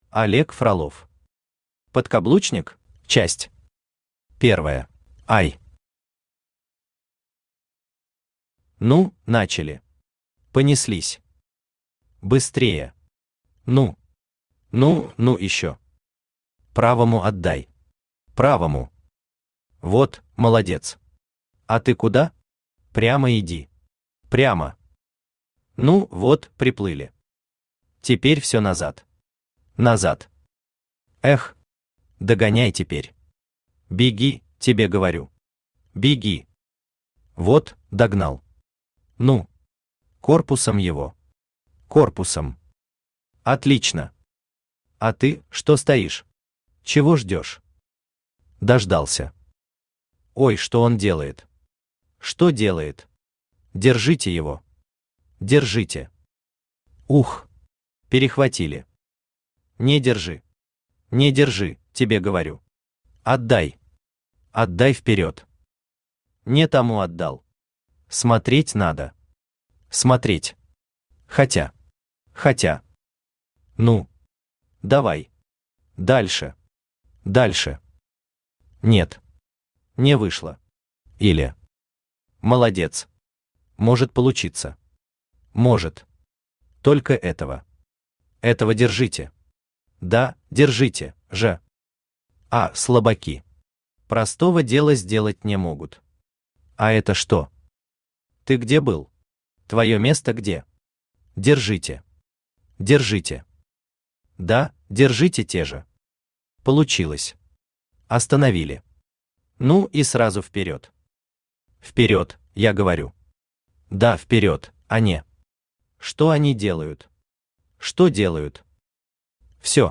Читает: Авточтец ЛитРес
Аудиокнига «Подкаблучник?».